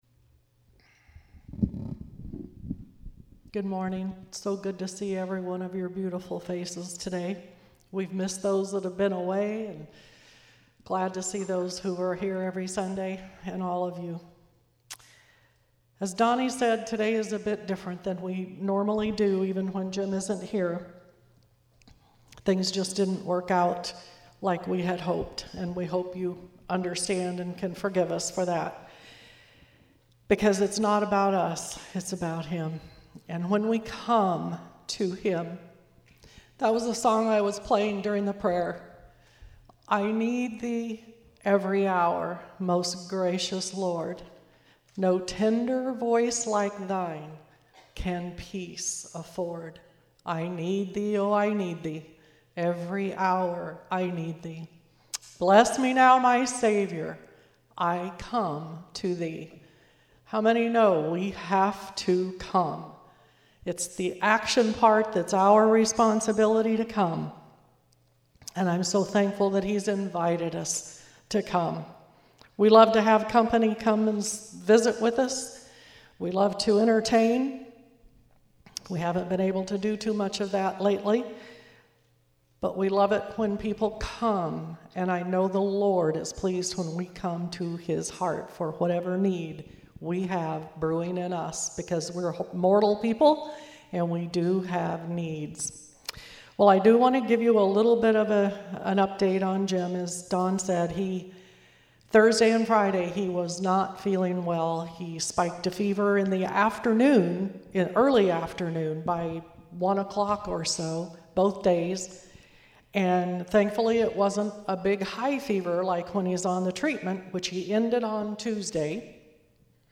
Worship And Prayer Service